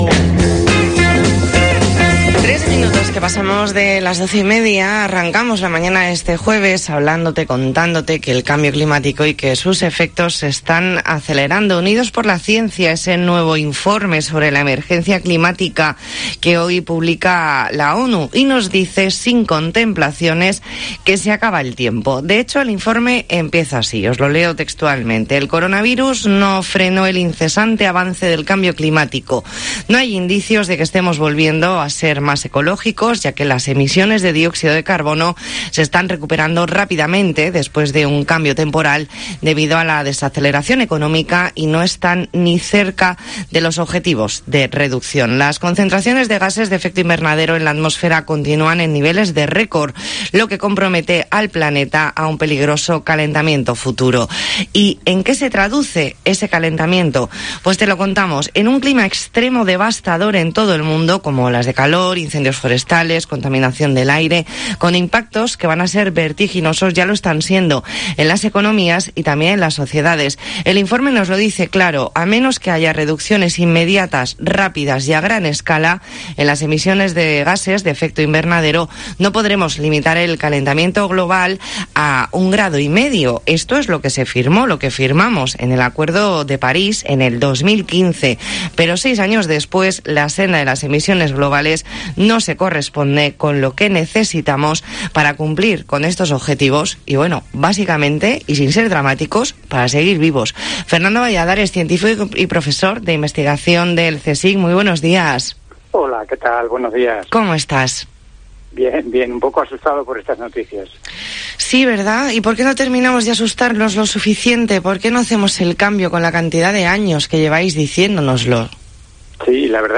Entrevista en La Mañana en COPE Más Mallorca, jueves 16 de septiembre de 2021.